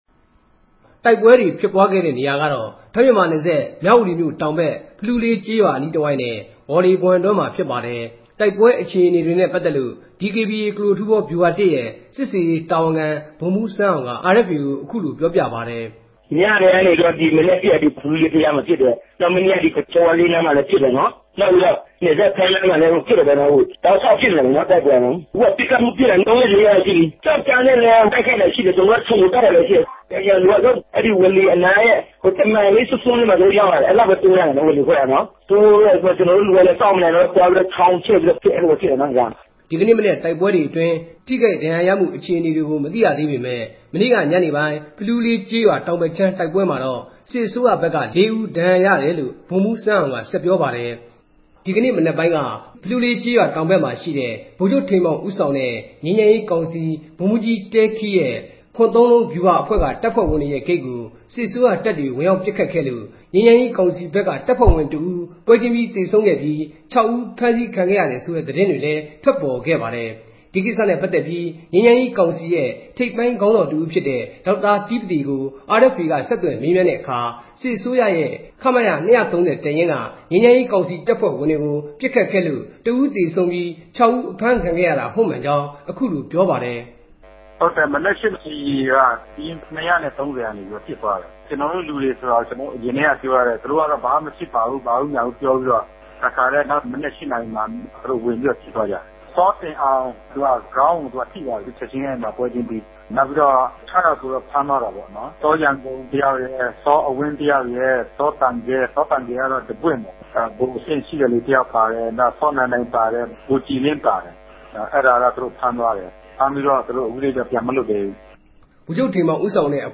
သတင်းပေးပို့ချက်